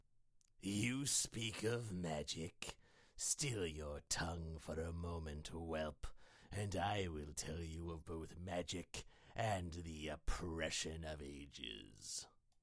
So, today, I thought I’d share a few takes for a few characters I auditioned for.
Audio glitch recording left this one very quiet, so you may need to turn up your volume, but only on this one.